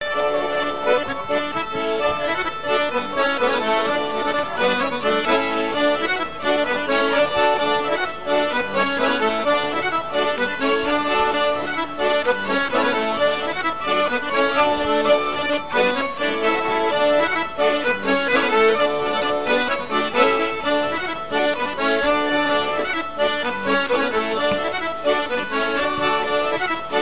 danse : branle : courante, maraîchine
partie de répertoire de Sounurs pour un bal
Pièce musicale inédite